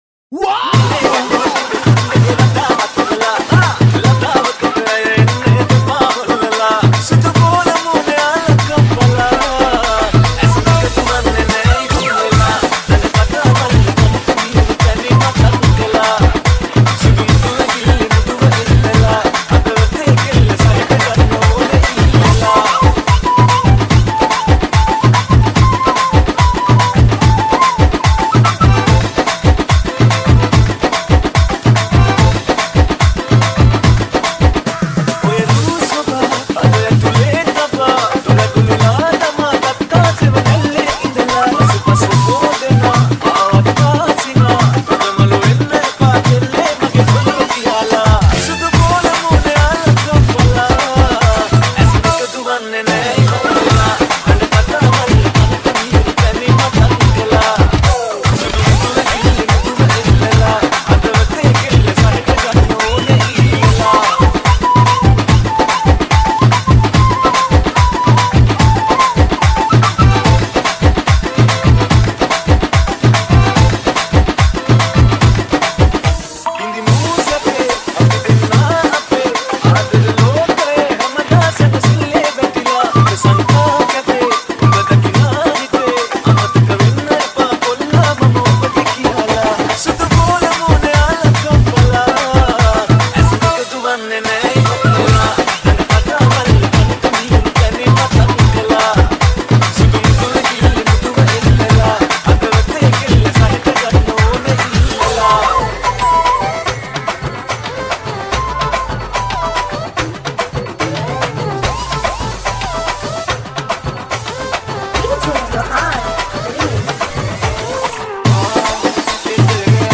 Dj Nonstop